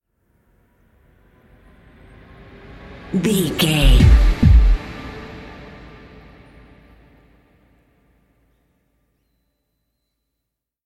Ionian/Major
D
synthesiser
drum machine